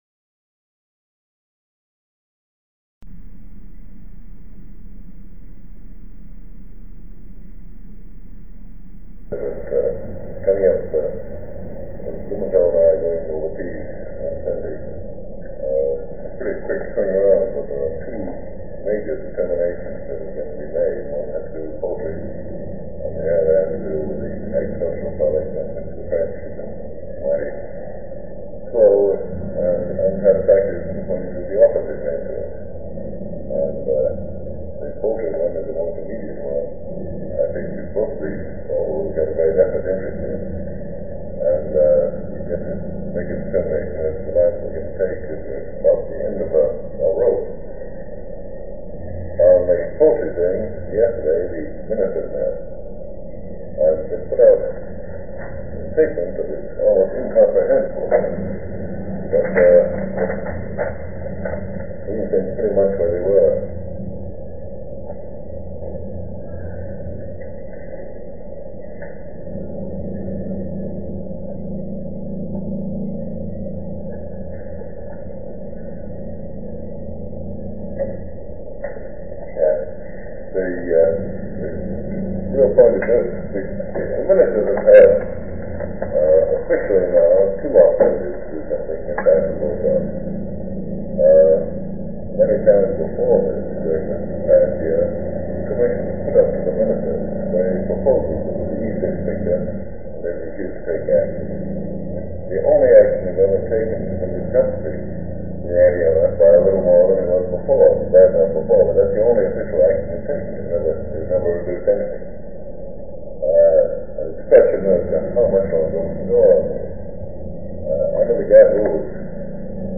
Sound recording of a meeting held on July 12, 1963, between President John F. Kennedy, Secretary of Agriculture Orville Freeman, Special Representative for Trade Negotiations Christian Herter, Under Secretary of State George Ball, Deputy Special Representative for Trade Negotiations W. Michael Blumenthal, and Roland Renne. They discuss trade issues with Europe. Poultry and California wine are mentioned specifically. The quality of this recording is poor.